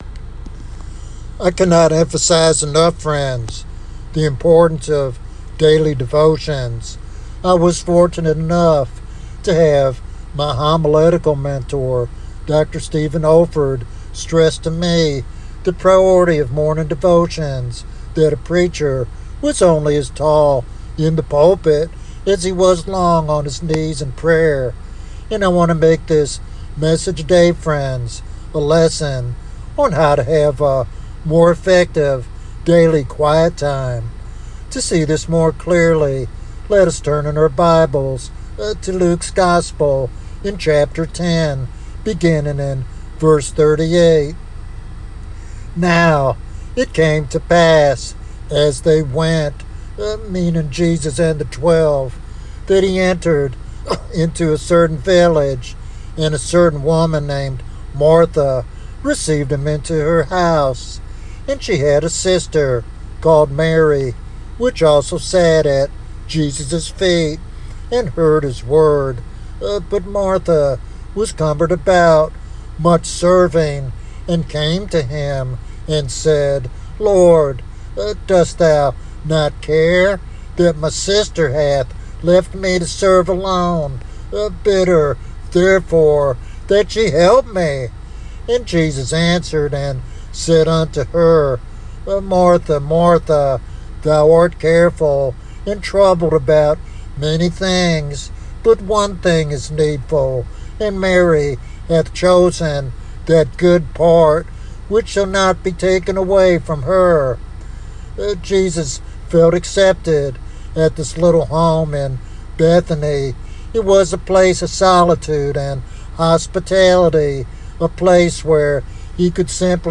In this teaching sermon